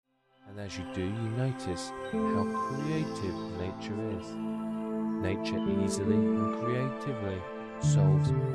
Creativity Enhancer Vocal Hypnosis MP3